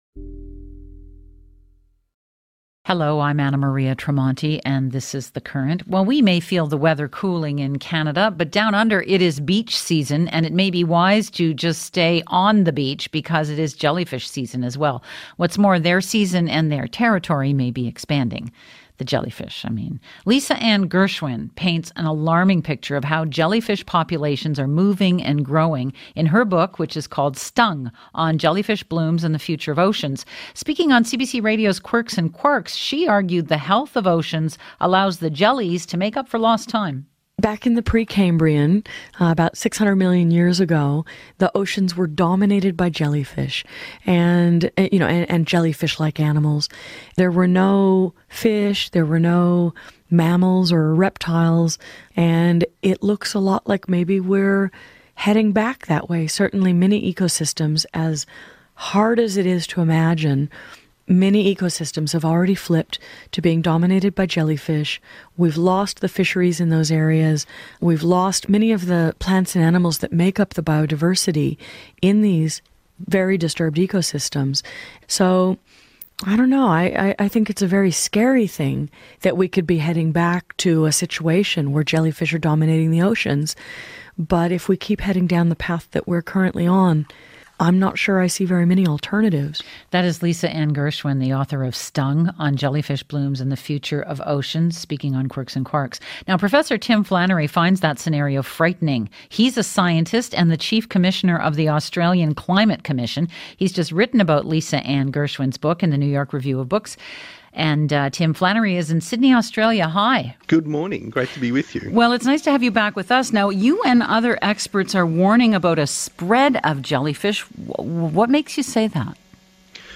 CBC Radio podcast